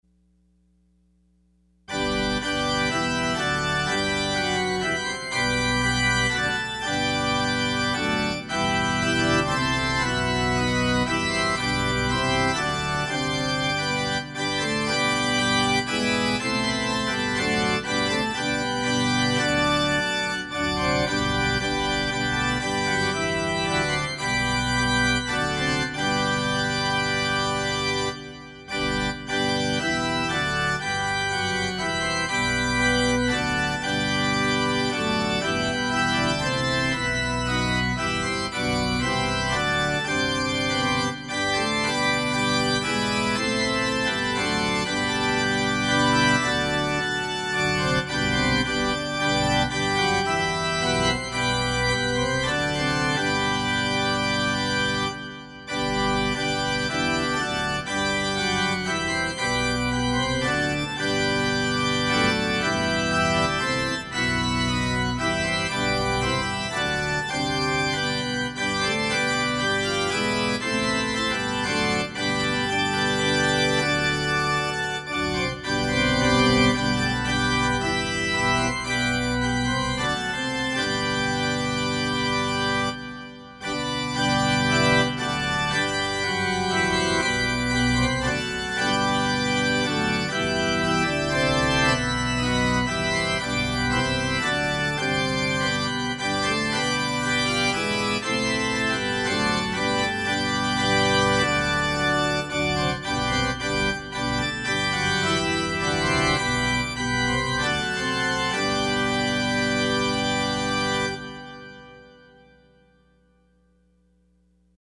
Closing Hymn – Ye servants of God #535